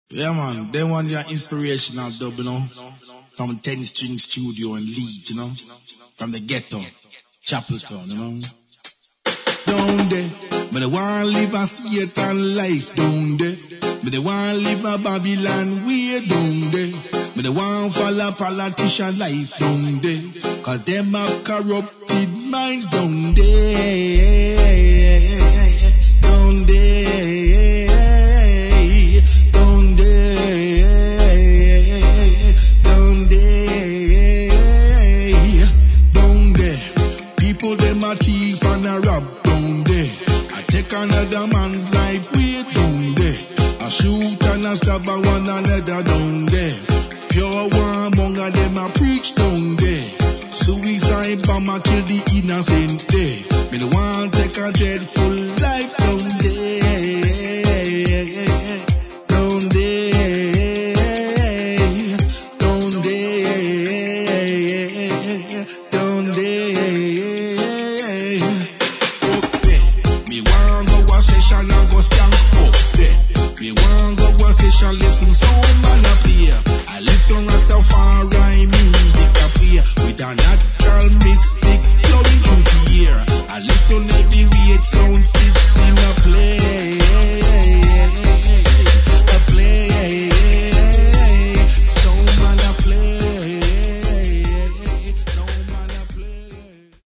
[ REGGAE / DUB / STEPPERS ]